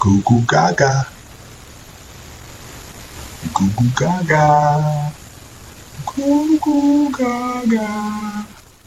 goo goo gagaaa Meme Sound Effect
goo goo gagaaa.mp3